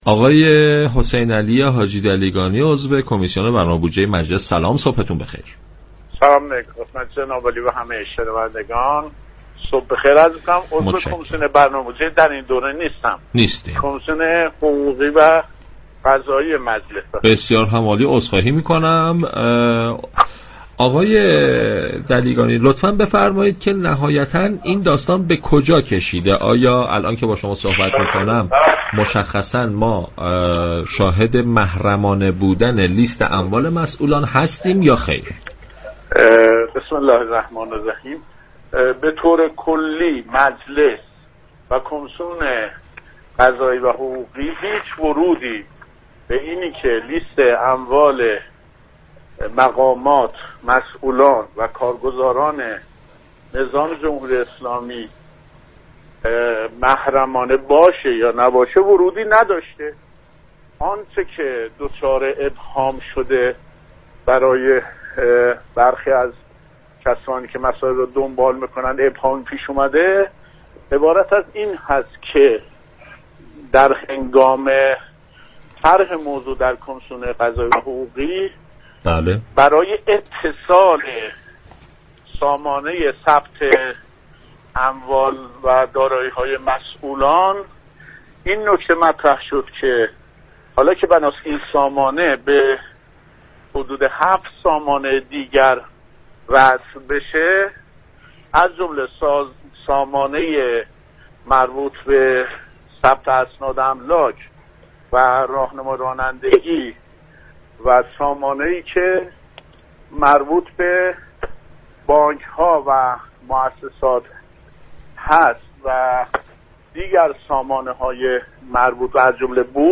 حسینعلی حاجی دلیگانی عضو كمیسیون حقوقی و قضایی مجلس شورای اسلامی در گفتگو با پارك شهر رادیو تهران